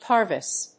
/ˈpɑɹvəs(米国英語), ˈpɑːvɪs(英国英語)/